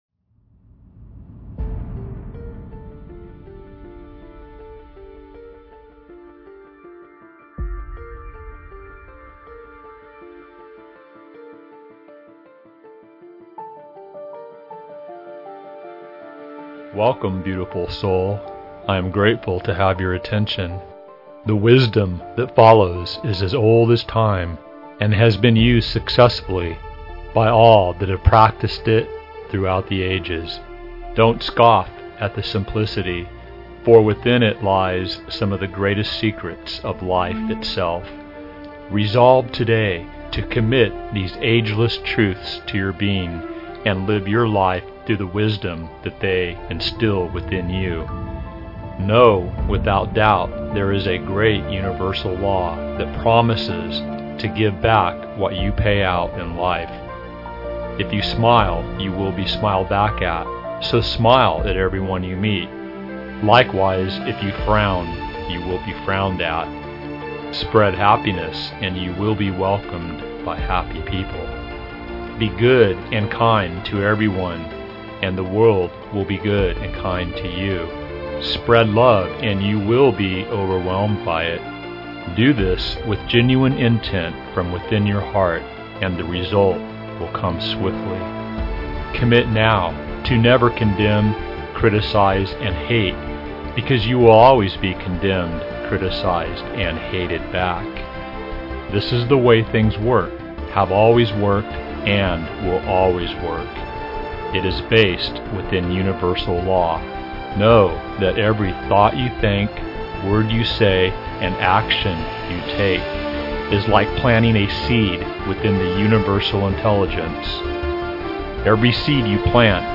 The Recipe ForAn Amazing Life Motitation is 23:17 minutes long with the message or “thought code” being repeated numerous times.